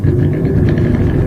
fanActivate.wav